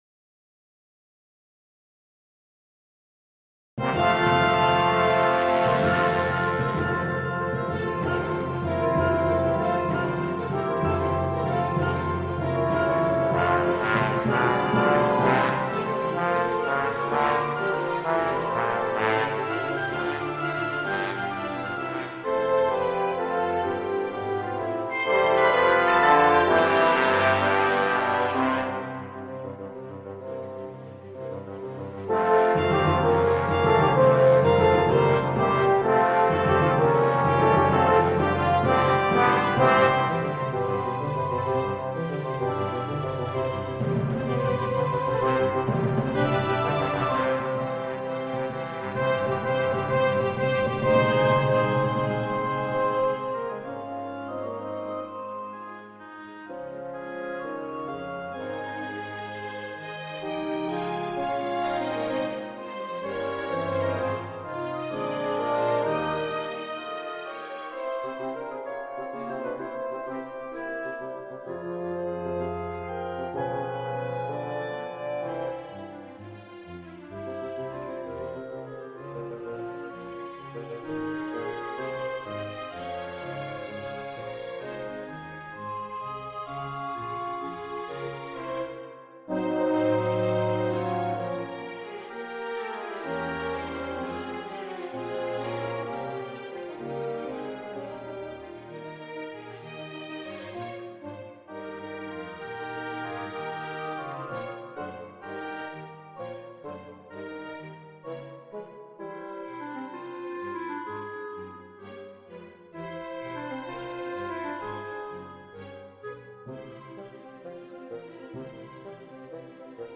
I'm generally reluctant to post digital versions of my compositions and am willing to wait a long time for a live r...